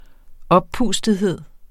Udtale [ ˈʌbˌpuˀsdəðˌheðˀ ]